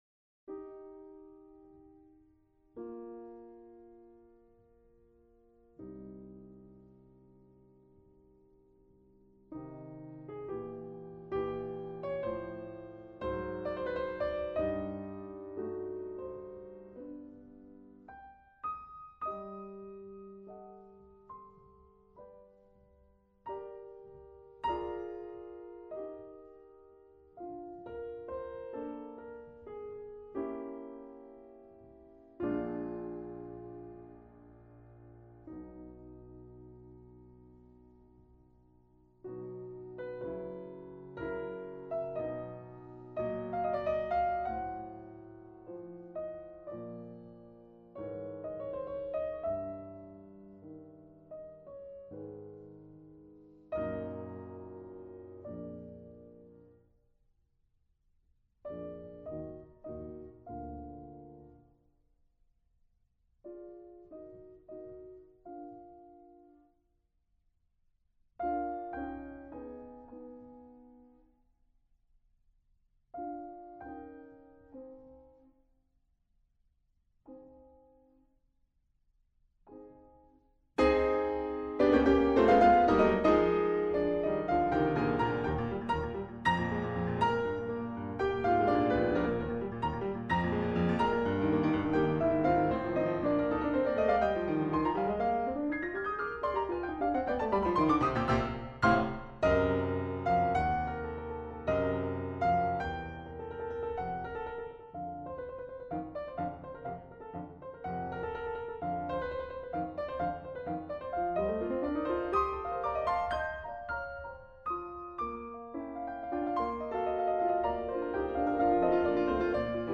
Répertoire pour Piano